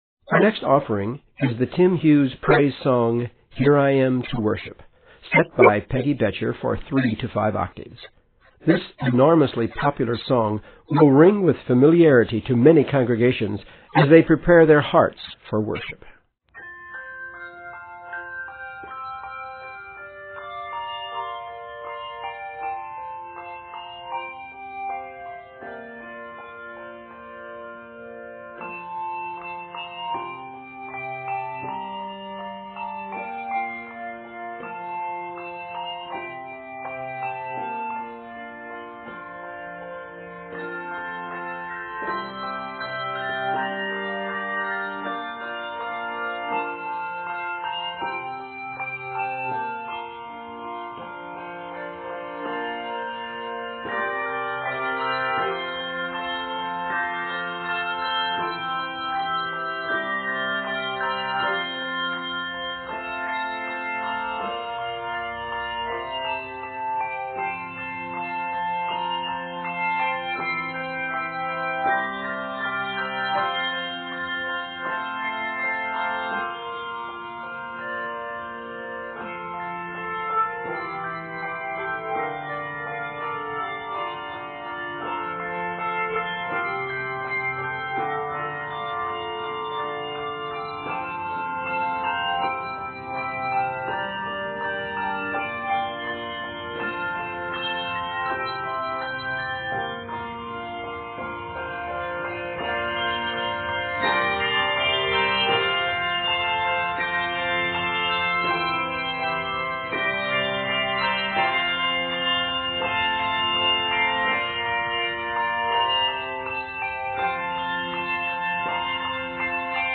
praise and worship song
for 3 to 5 octave handbell choir